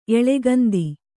♪ eḷegandi